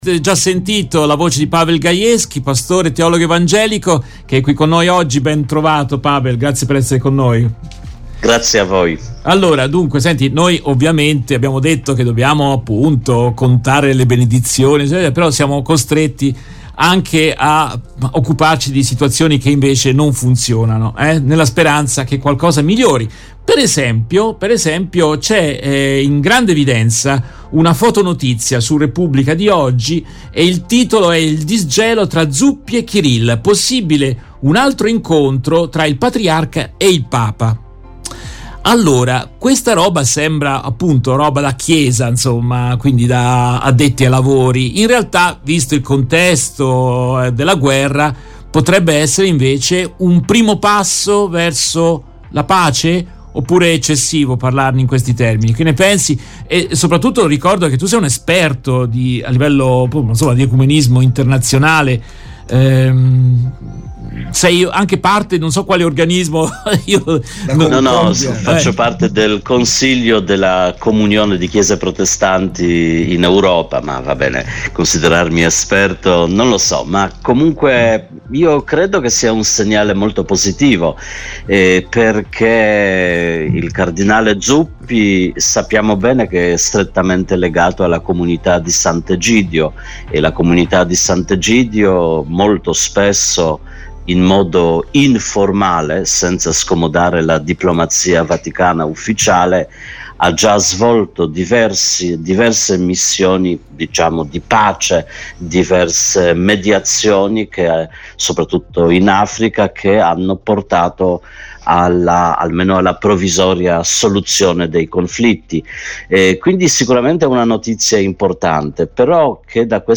In questa intervista tratta dalla diretta RVS del 30 giugno 2023